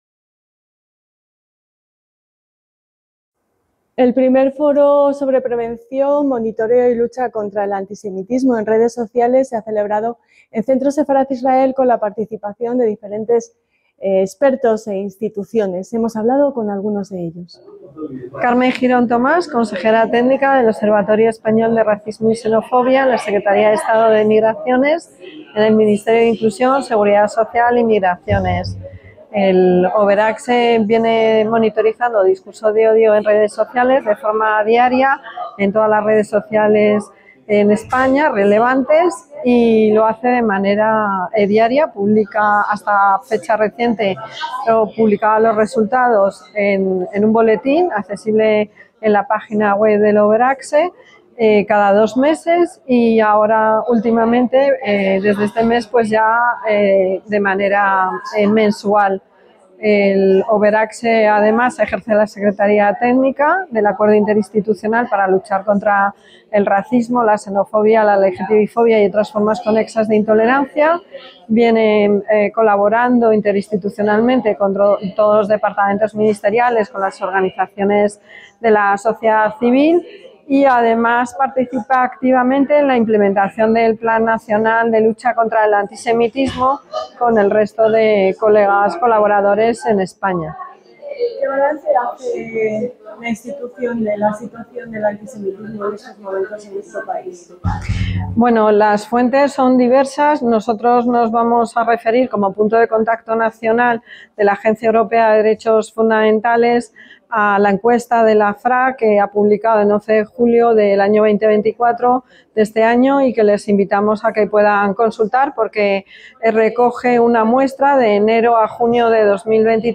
EL REPORTAJE - El I Foro sobre prevención, monitoreo y lucha contra el antisemitismo en entornos digitales organizado por Centro Sefarad-Israel ha querido explorar cómo las dinámicas de la comunicación en línea, incluidas las redes sociales, foros y otras plataformas, facilitan la difusión de mensajes de odio y desinformación.